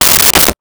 Toilet Paper Dispenser 04
Toilet Paper Dispenser 04.wav